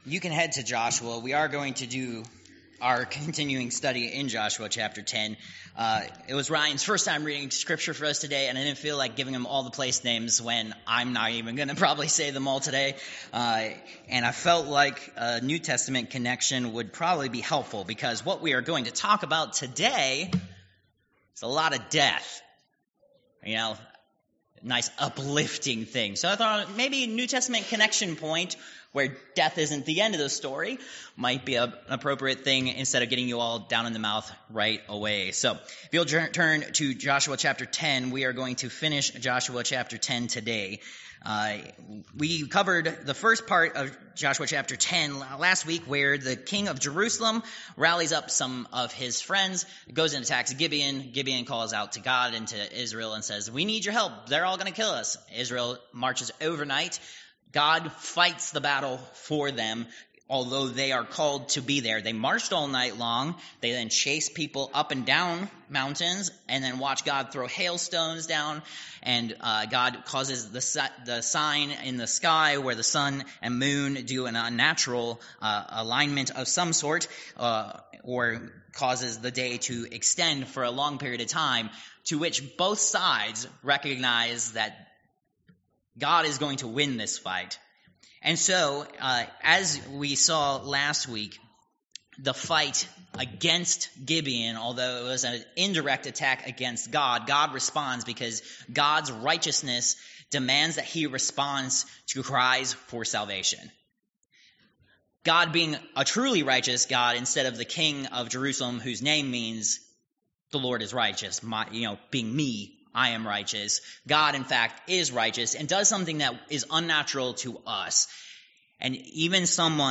Josh. 10:16-43 Service Type: Worship Service Download Files Notes « Protected